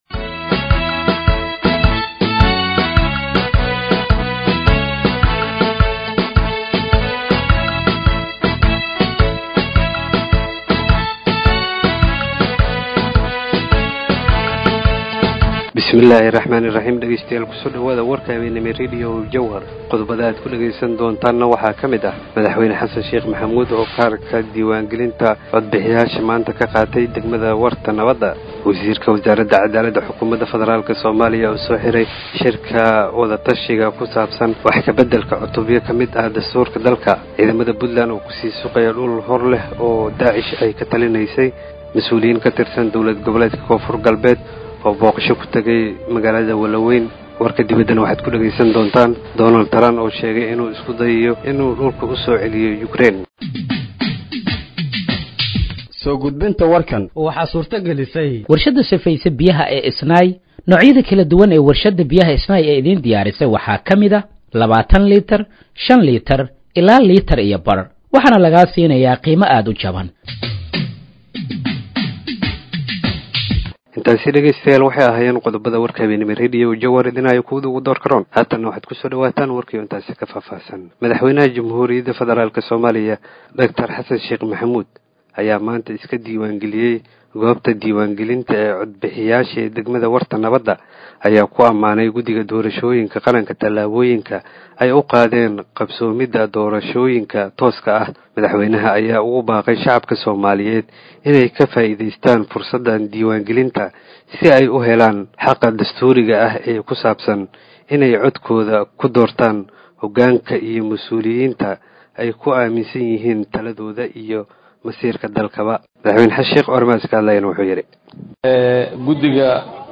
Dhageeyso Warka Habeenimo ee Radiojowhar 12/08/2025